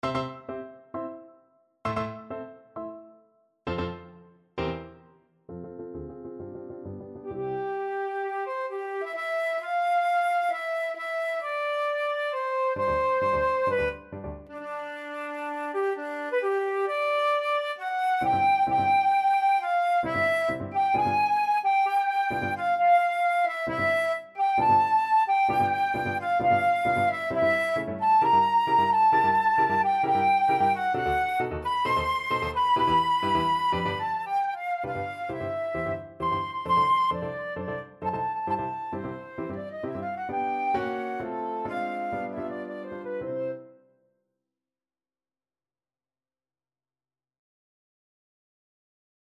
Flute version
2/2 (View more 2/2 Music)
Classical (View more Classical Flute Music)